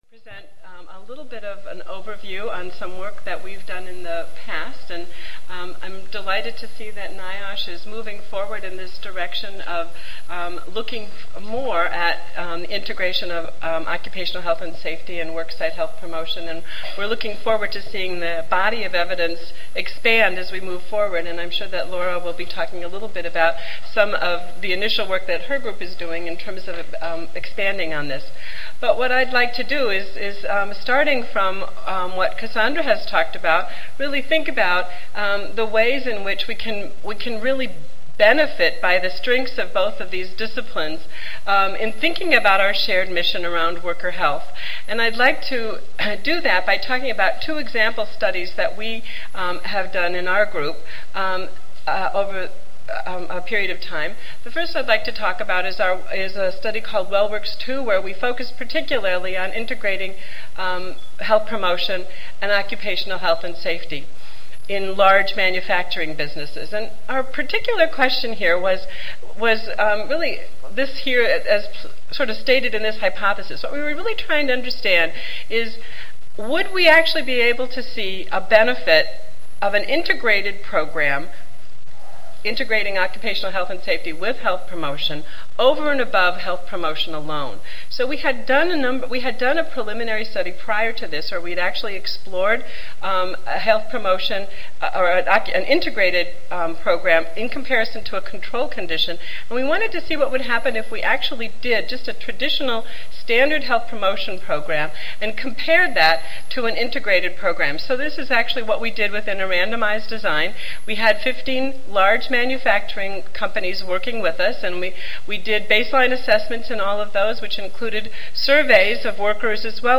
The union and industry perspectives on value, concerns and priorities for the health protection/health promotion model will be presented by a panelist from a union and a panelist representing the industry. The session concludes with a discussion on moving the field forward.